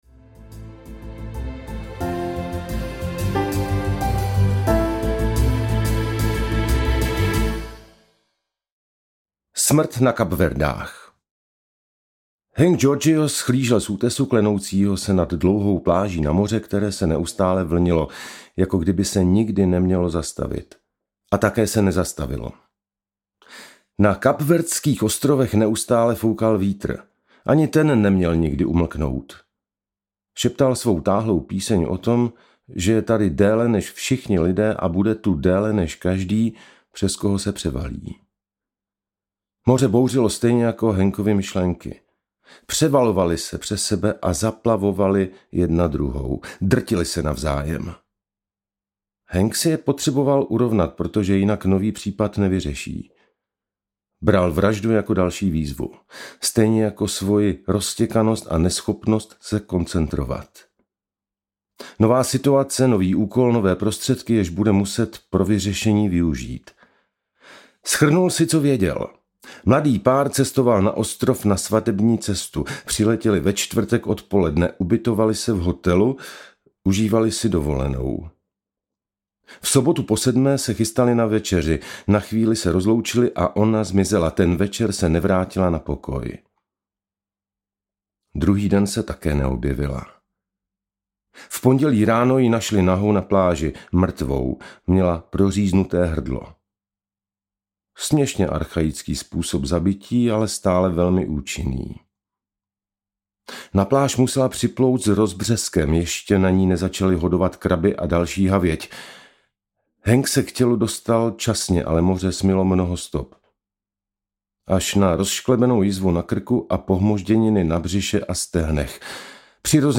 Ukázka z knihy
mrtve-zeny-audiokniha